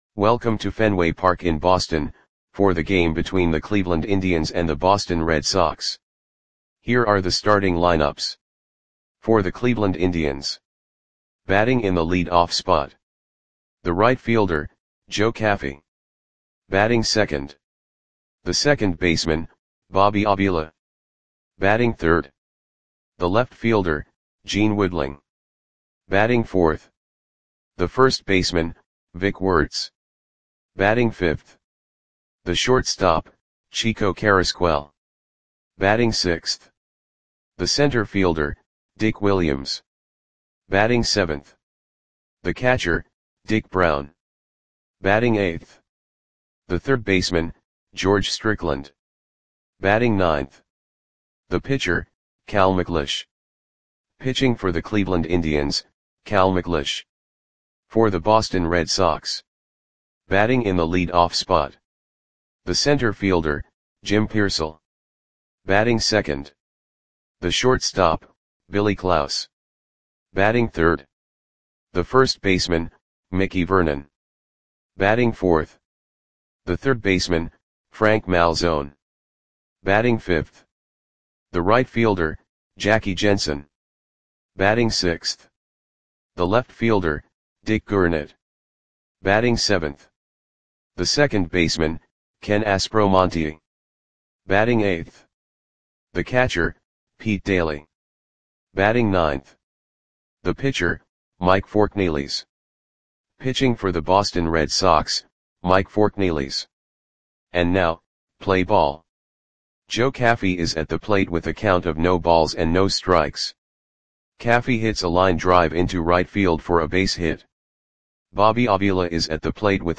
Audio Play-by-Play for Boston Red Sox on September 14, 1957
Click the button below to listen to the audio play-by-play.